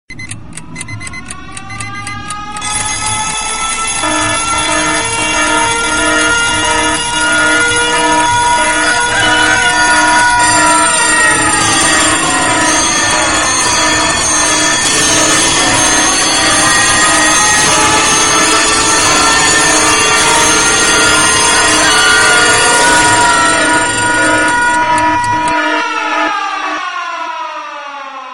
Звуки для пробуждения
Все будильники в одной мелодии